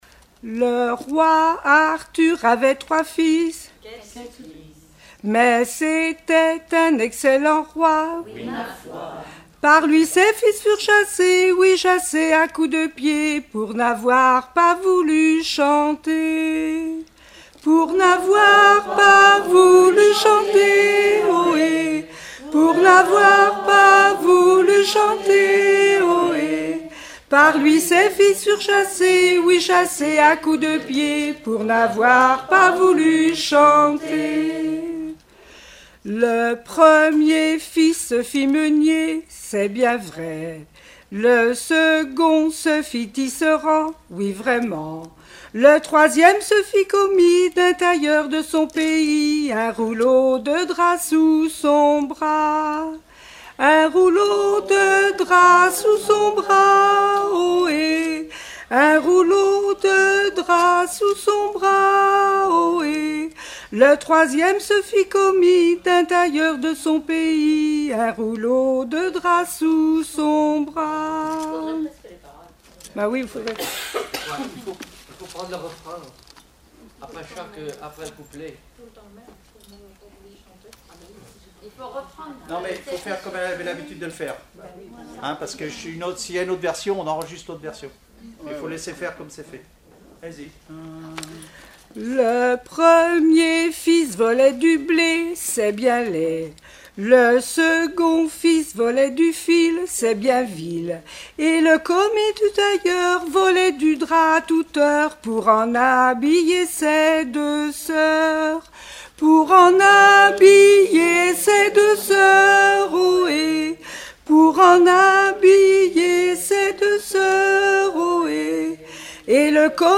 Mémoires et Patrimoines vivants - RaddO est une base de données d'archives iconographiques et sonores.
Chansons traditionnelles et populaires
Pièce musicale inédite